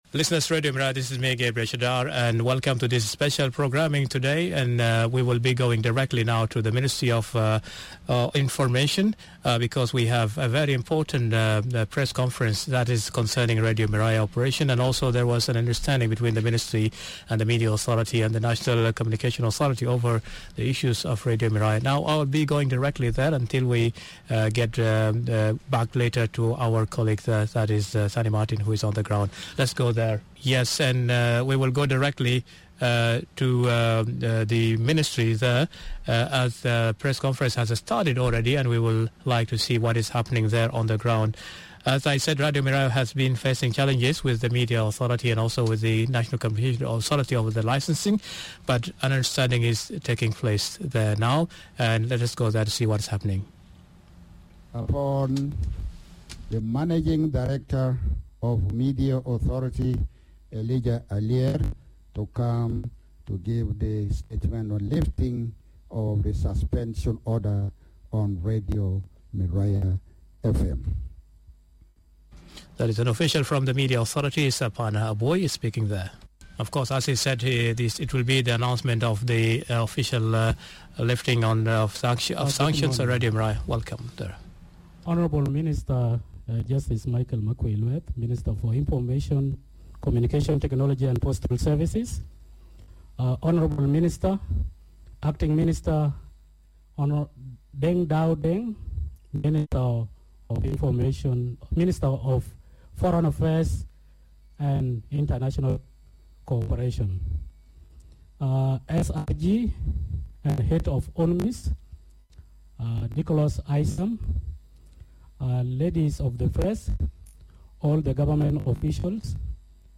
Press Conference By Government of South Sudan on Radio Miraya